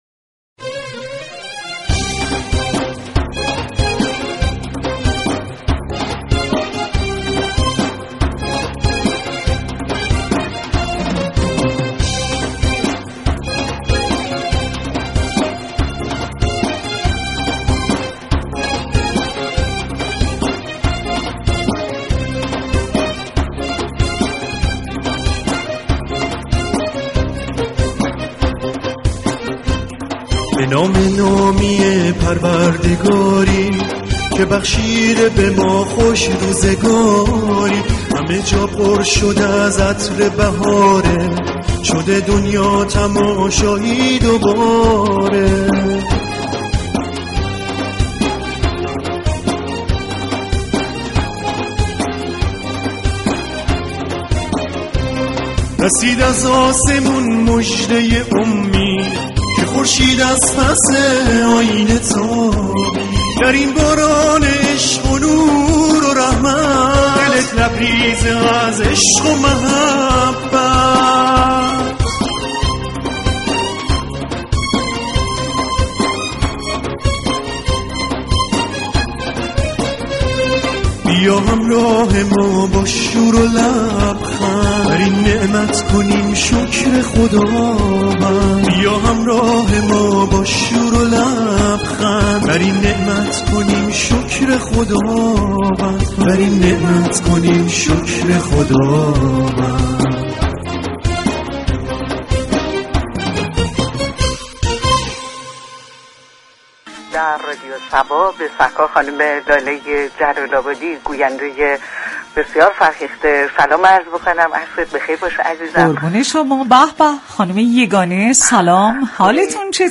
ویژه برنامه عصر همدلی به مناسبت هفته وحدت ارتباط استودیویی با مركز گستان داشت.